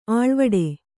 ♪ āḷvaḍe